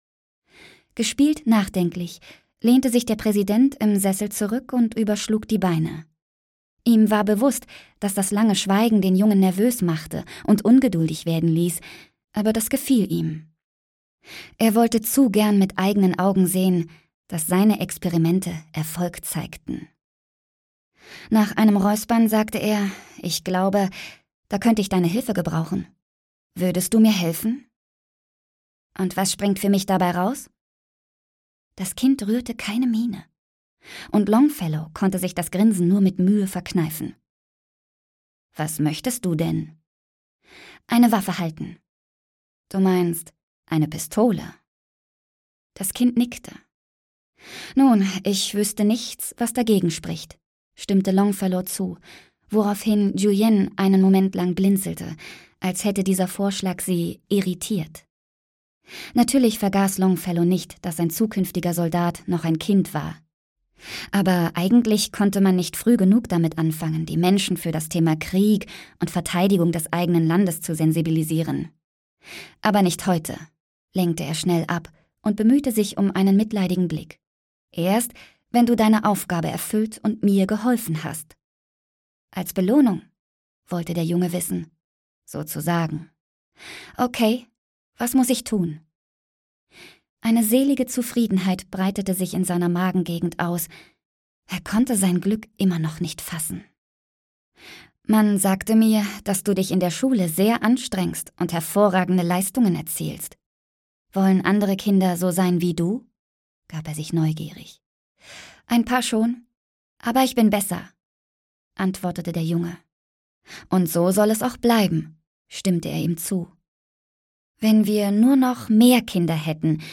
Spark (Die Elite 1) - Vivien Summer - Hörbuch